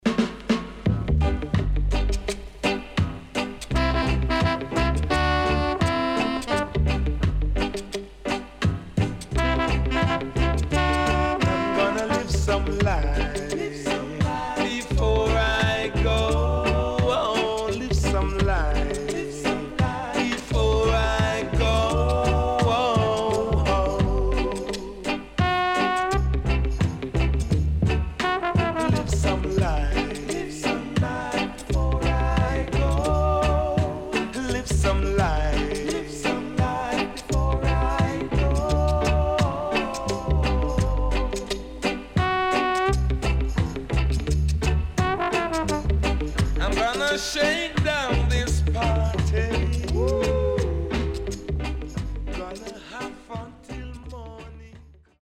Nice Roots Vocal.Irie Feelings.Good Condition